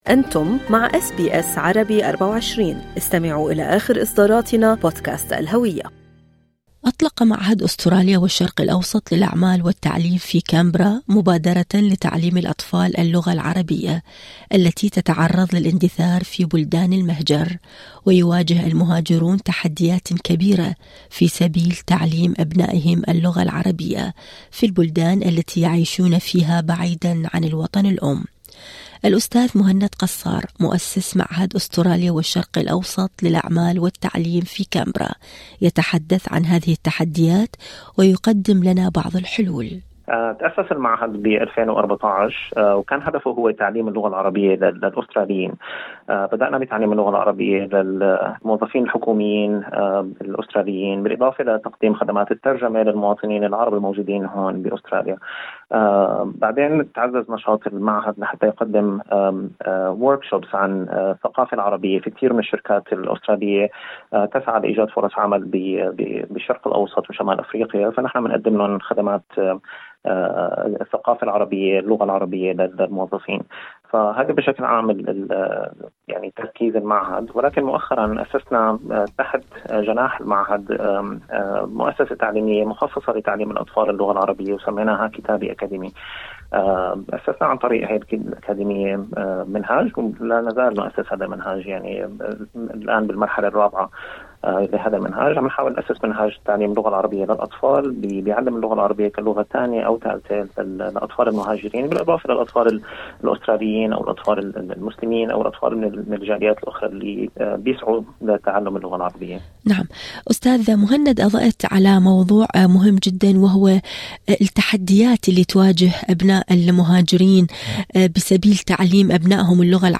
المزيد في المقابلة الصوتية اعلاه هل أعجبكم المقال؟